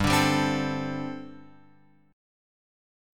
Gsus2sus4 chord {3 5 5 5 x 5} chord